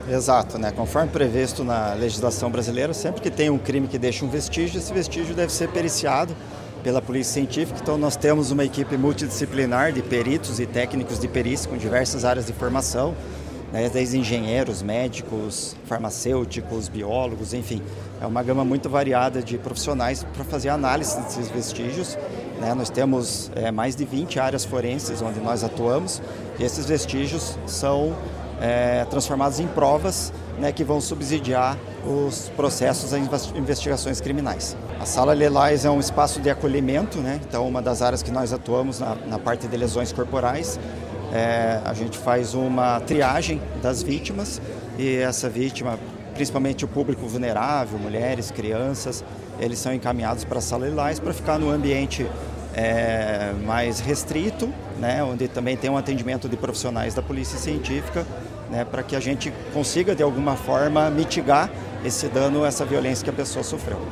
Sonora do diretor-geral da Polícia Científica do Paraná, Ciro Pimenta, sobre a nova sede da Polícia Científica de Telêmaco Borba